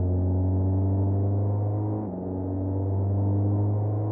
engine.ogg